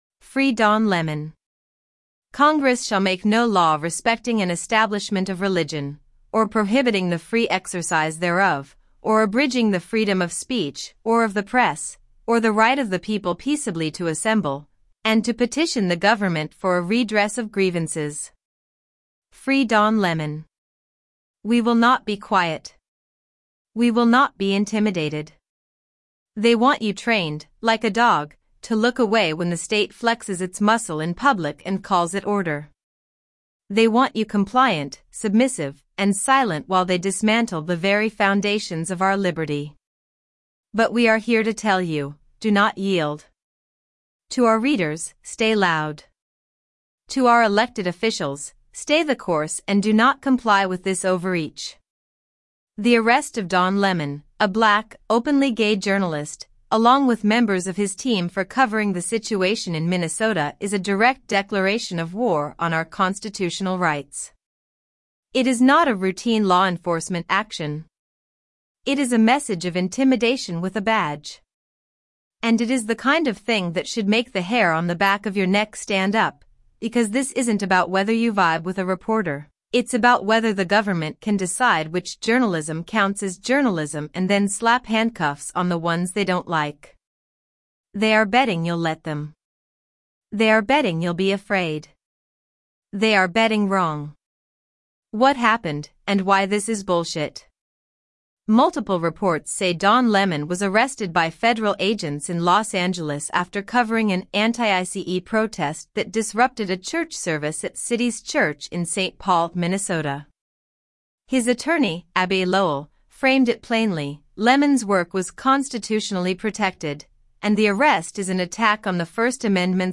AI Editorial Voice